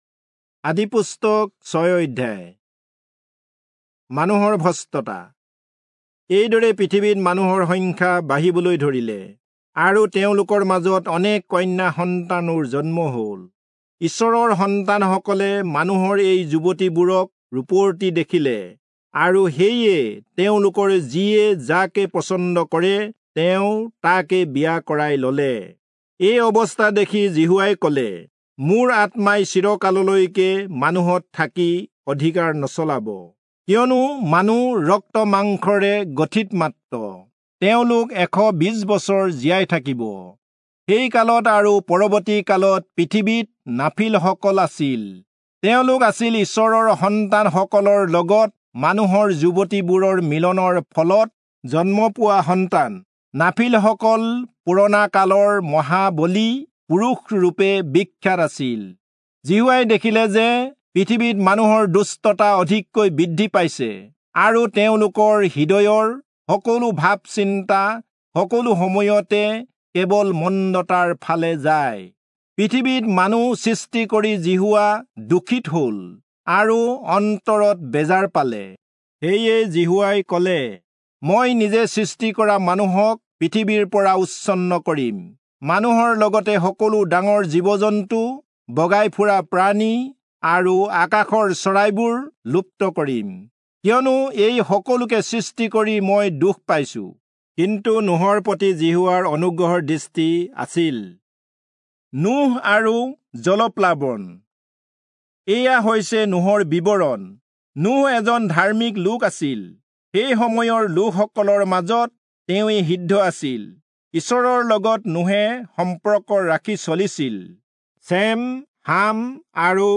Assamese Audio Bible - Genesis 18 in Mov bible version